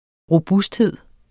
Udtale [ ʁoˈbusdˌheðˀ ]